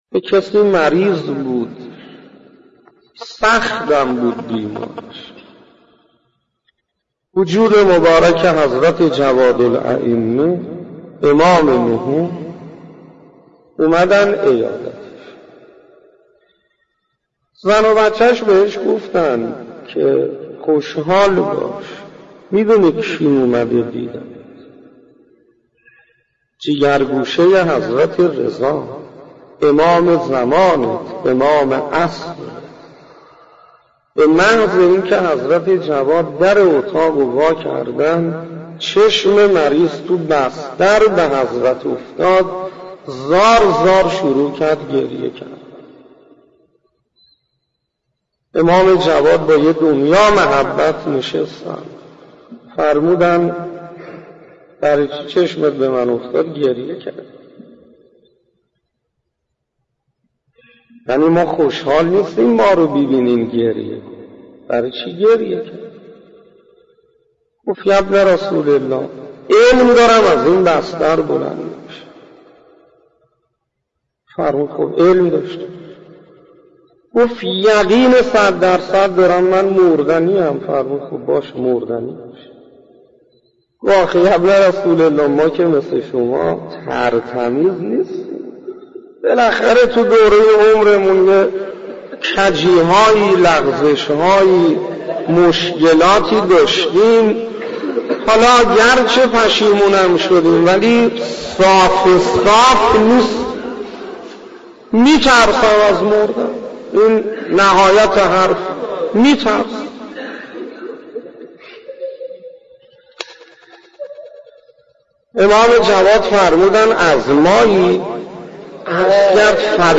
حکمت امام جواد علیه السلام سخنرانی استاد انصاریان